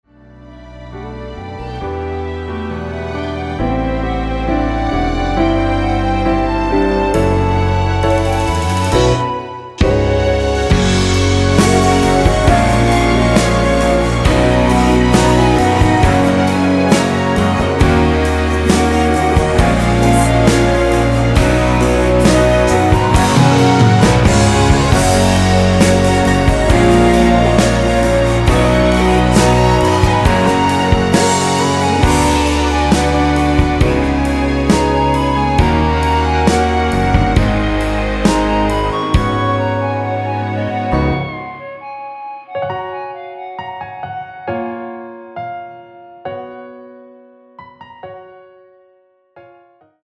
이곡의 코러스는 미리듣기에 나오는 부분밖에 없으니 참고 하시면 되겠습니다.
원키에서(+2)올린 멜로디와 코러스 포함된 MR입니다.(미리듣기 확인)
앞부분30초, 뒷부분30초씩 편집해서 올려 드리고 있습니다.
중간에 음이 끈어지고 다시 나오는 이유는